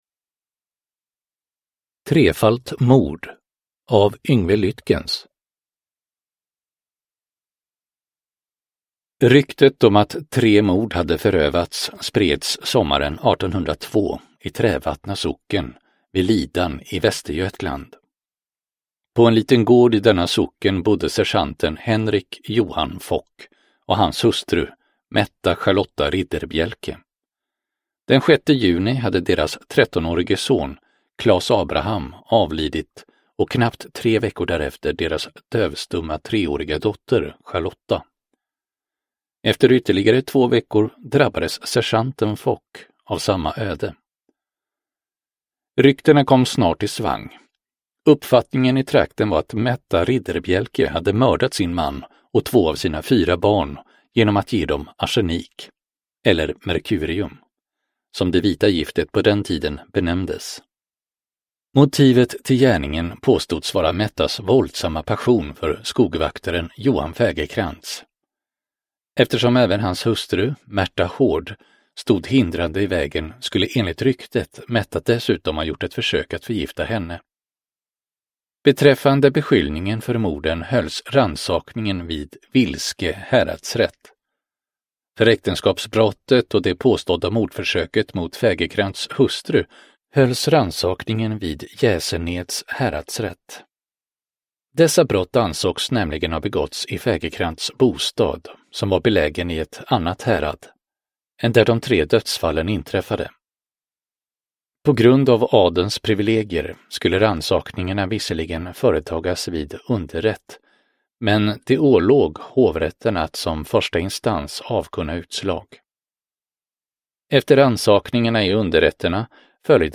Trefalt mord? : Historiska mord del 7 – Ljudbok – Laddas ner